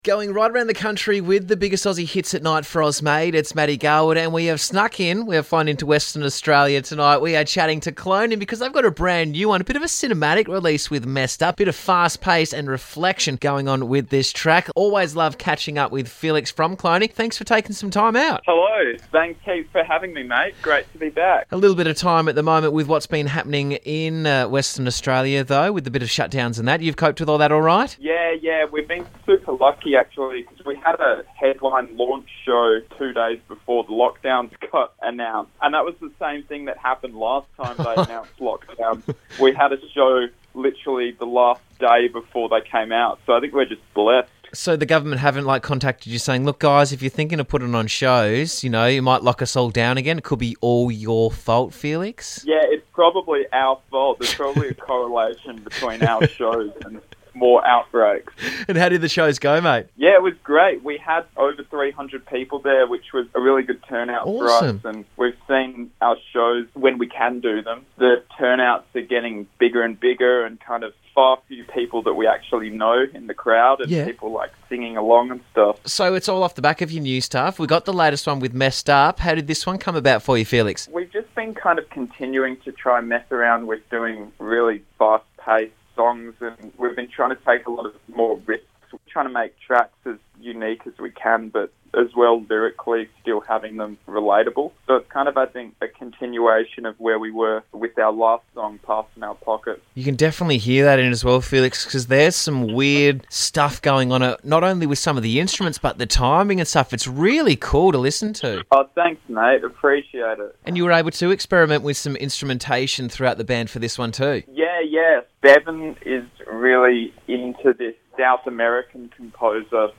a fast paced and reflective track